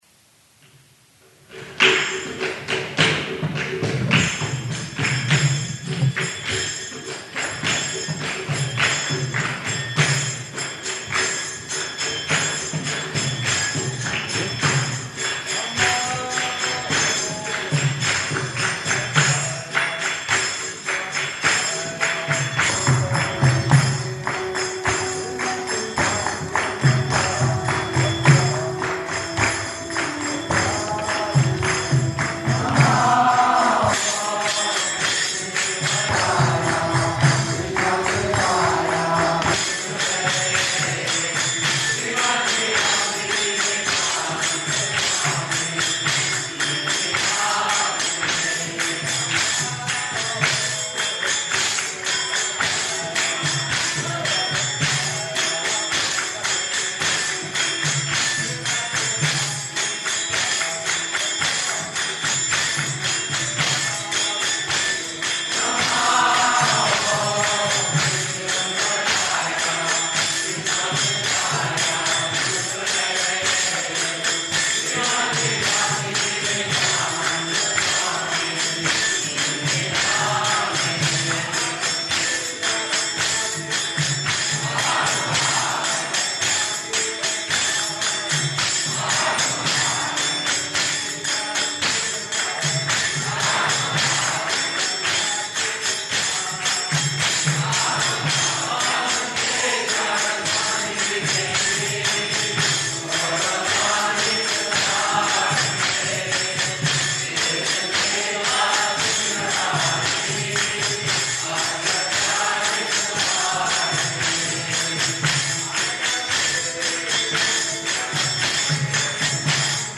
Lecture
Lecture --:-- --:-- Type: Lectures and Addresses Dated: July 4th 1971 Location: Los Angeles Audio file: 710704LE-LOS_ANGELES.mp3 [ kīrtana ] [ prema-dhvani ] Prabhupāda: Thank you very much.